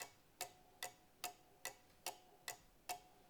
cuckoo_tick.R.wav